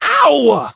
hotshot_hurt_01.ogg